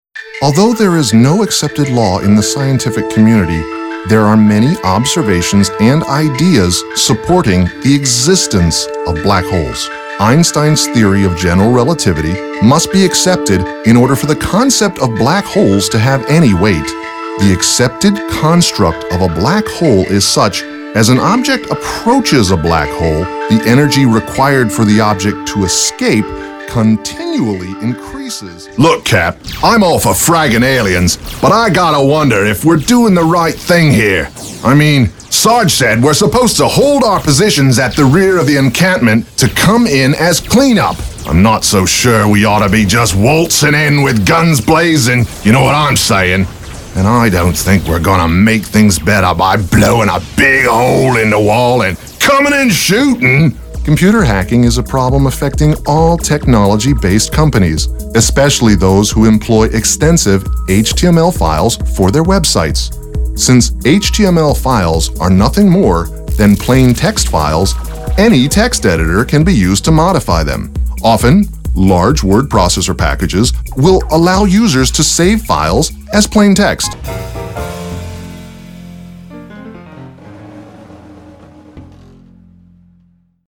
Male
English (North American)
Older Sound (50+)
Narration
Selection Of Mock Narrations
1104narrativedemo.mp3